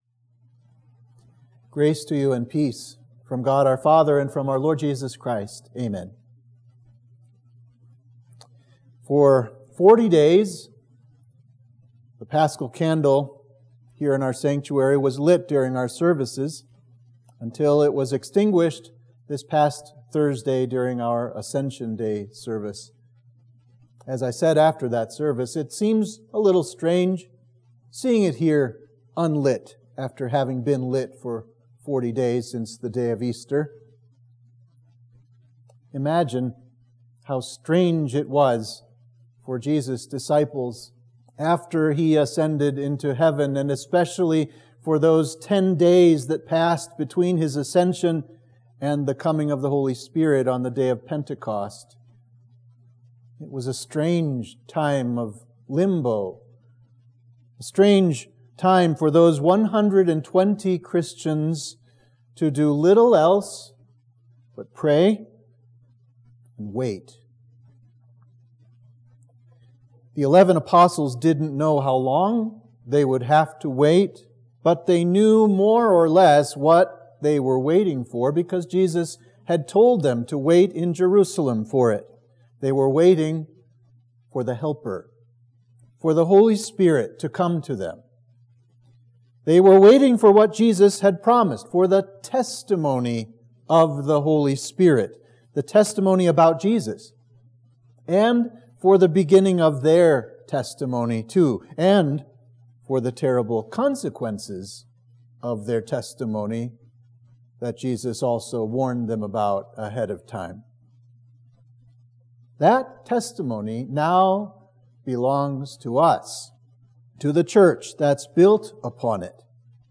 Sermon for Exaudi – the Sunday after Ascension